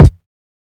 Kick (RnB).wav